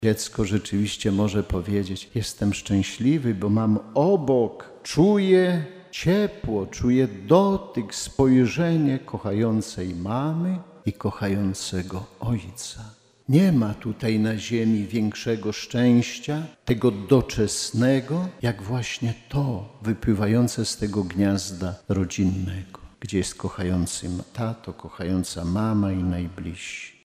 W uroczystość Świętej Rodziny bp warszawsko-praski przewodniczył uroczystej Mszy Św. w parafii Świętej Rodziny na stołecznym Zaciszu.